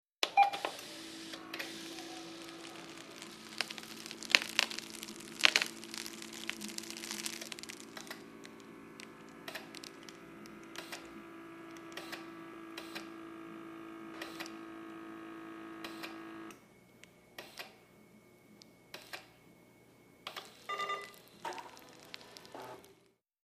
Blood Pressure Sphygmomanometer | Sneak On The Lot